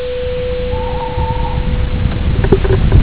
inserisco qui di seguito una registrazione: si sente in sottofondo un verso, purtroppo molto basso, confermate che si tratta di Allocco (Strix aluco)?
Verso Allocco allocco.wav